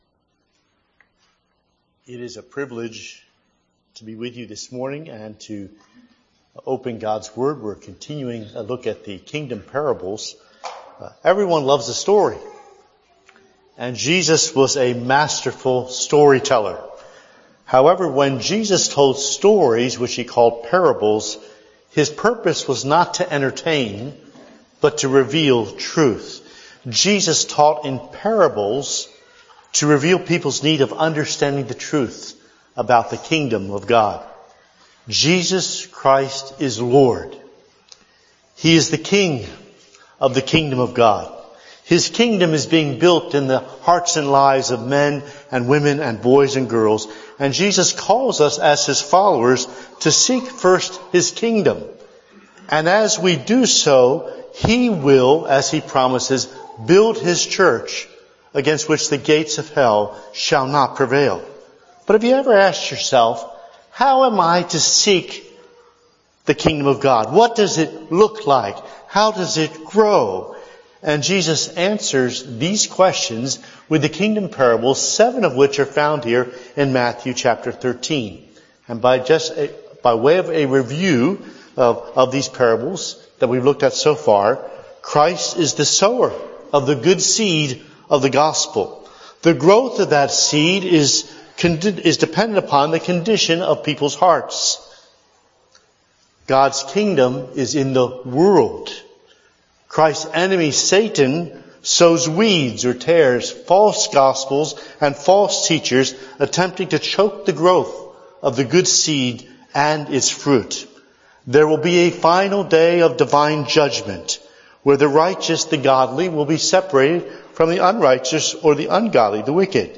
Only the sermon audio is available here.